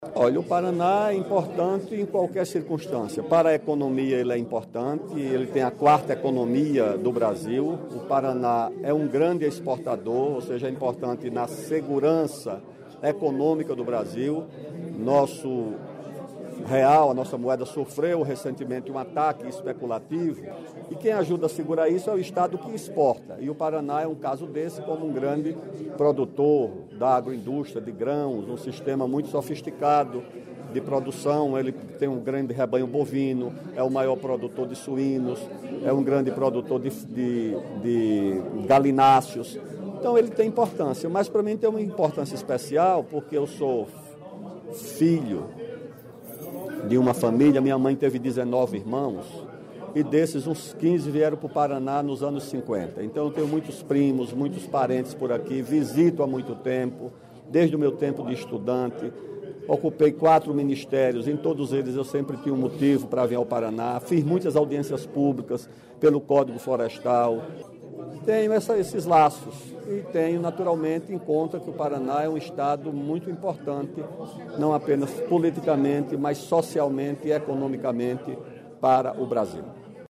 O ex-ministro Aldo Rebelo esteve na Assembleia Legislativa do Paraná nesta segunda-feira (28) para uma visita de cortesia aos deputados estaduais.
Pré-candidato à Presidência da República pelo Solidariedade, Rebelo destacou a importância do Paraná no contexto nacional. Confira a entrevista.